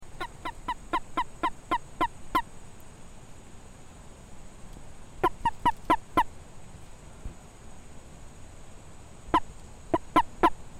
盤古蟾蜍 Bufo bankorensis
錄音地點 花蓮縣 秀林鄉 沙卡礑
錄音環境 山蘇園的步道上
行為描述 1隻鳴叫